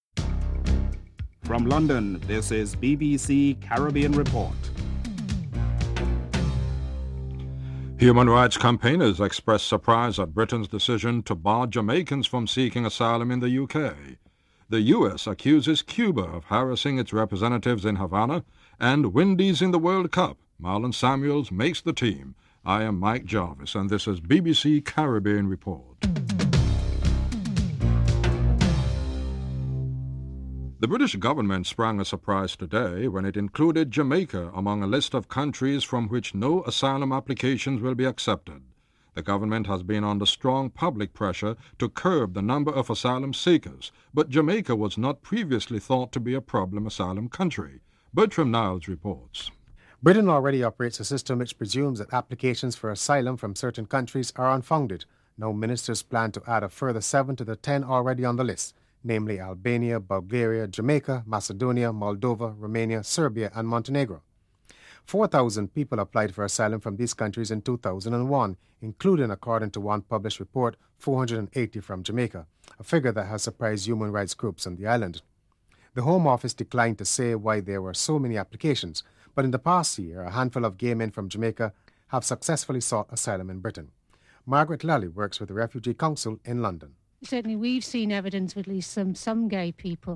Prime Minister Lester Bird reveals at a press conference that the reason for government’s initial delayed response was Mr. Standford’s demand for a freehold on land.